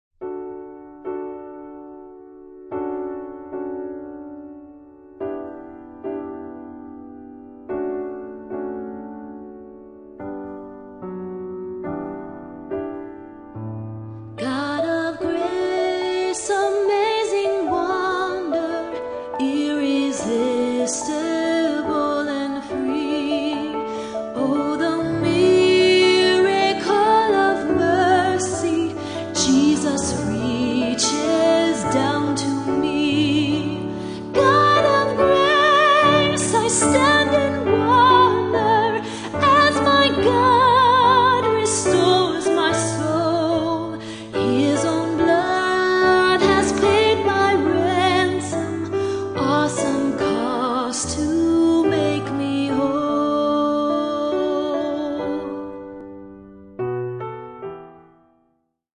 Voicing: Medium Voice